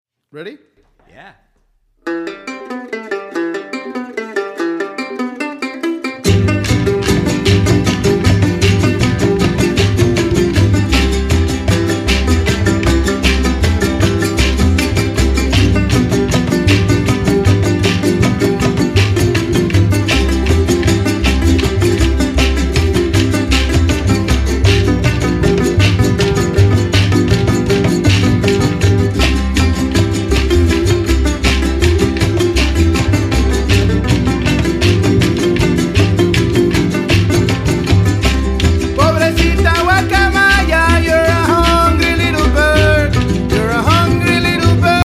. it’s all there in its multi-instrumental glory.